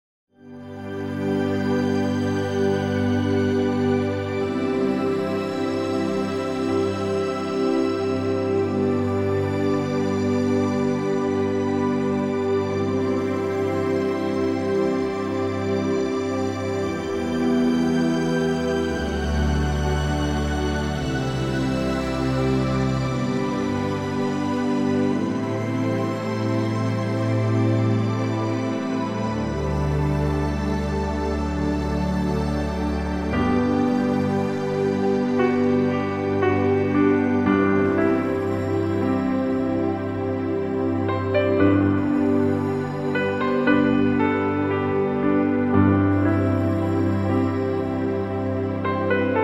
Fantastische Meditatonsmusik mit 16 Klavierstücken